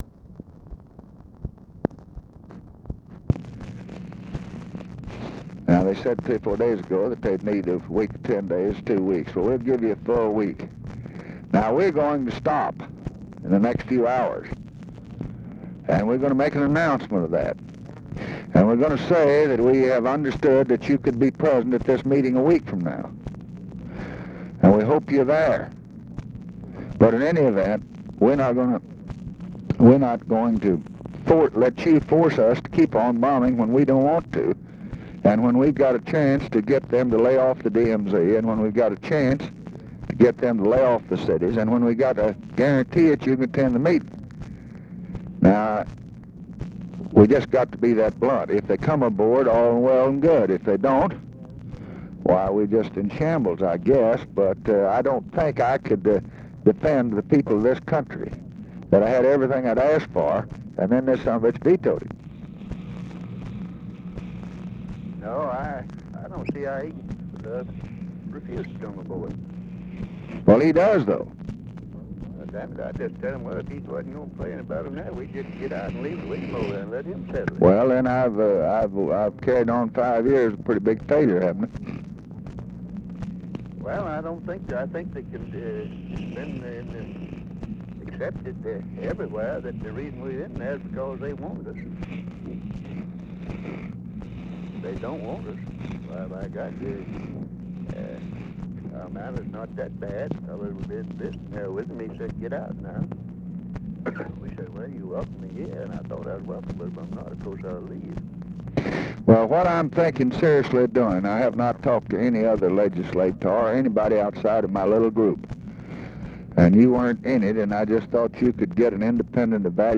Conversation with RICHARD RUSSELL, October 30, 1968
Secret White House Tapes